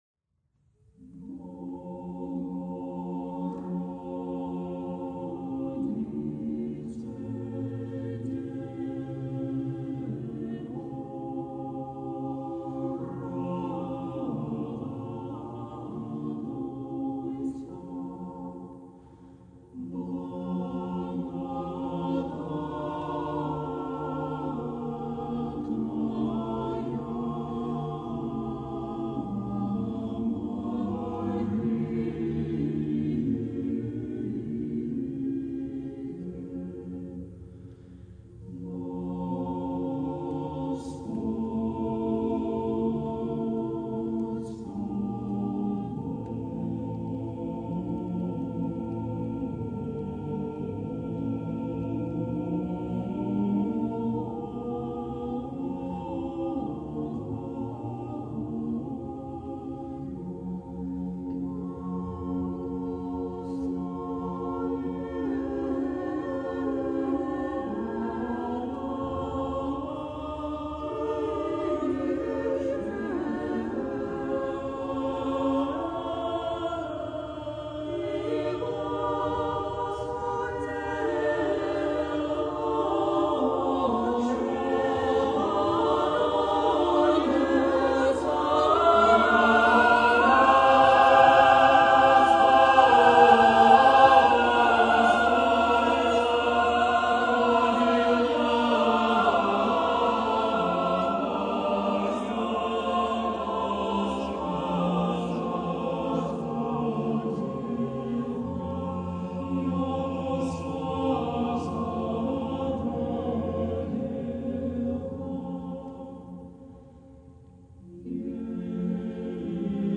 Предлагаем для прослушивания песнопения хорового коллектива.